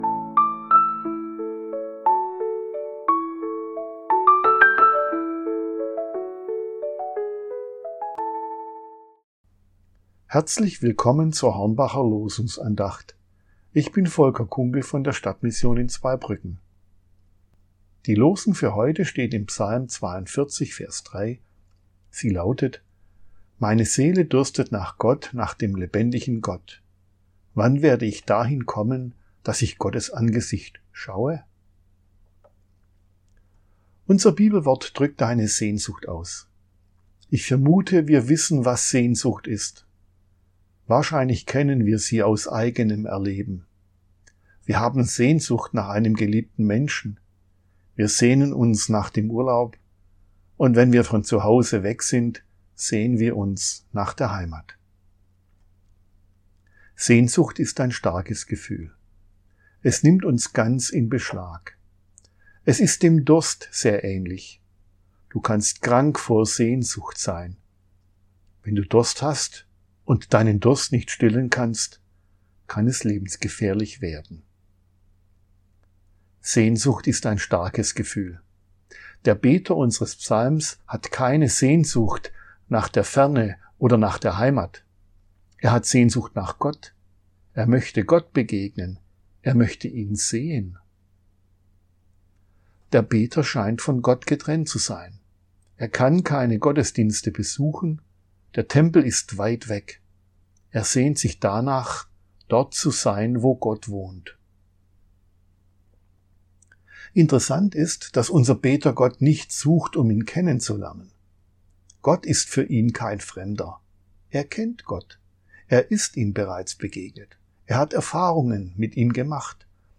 Losungsandacht für Mittwoch, 21.01.2026